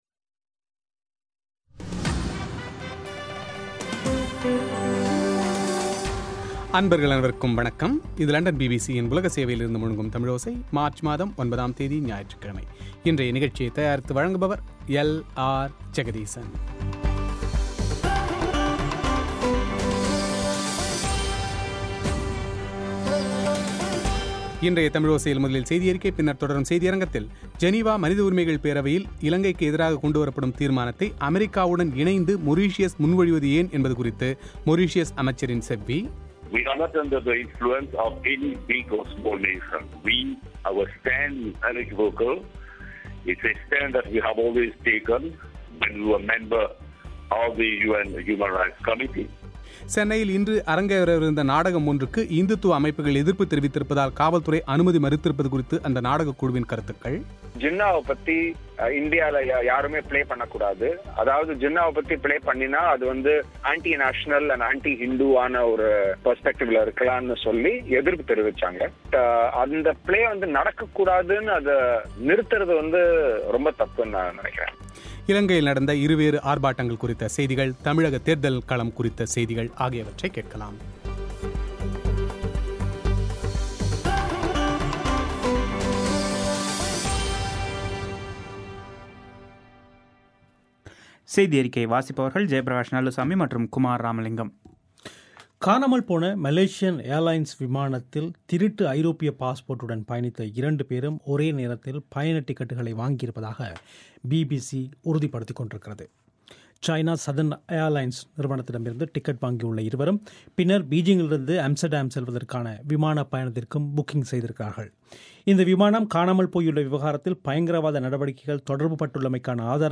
ஜெனீவா மனித உரிமைகள் பேரவையில் இலங்கைக்கு எதிராக கொண்டுவரப்படும் தீர்மானத்தை அமெரிக்காவுடன் இணைந்து மொரீஷியஸ் முன்மொழிவது ஏன் என்பது குறித்து மொரீஷியஸ் அமைச்சரின் செவ்வி;